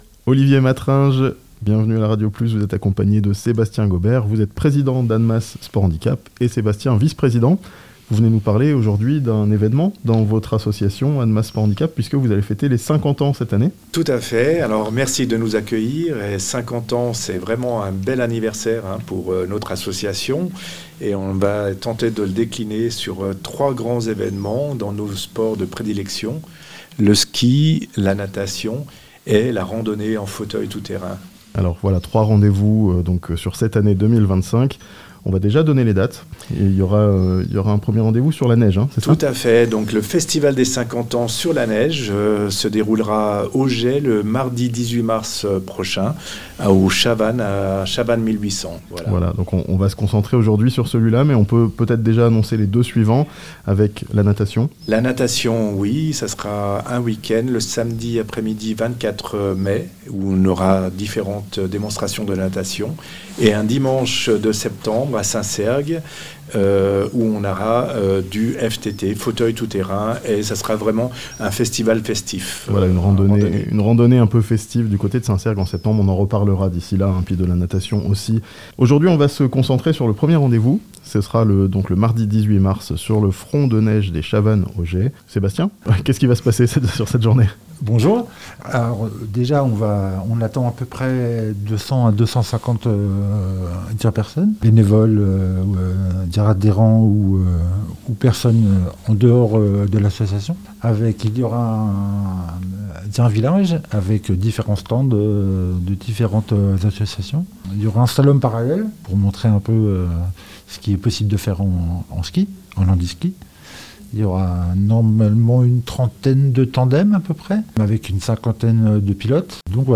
Annemasse Sports Handicap fête ses 50 ans (interview)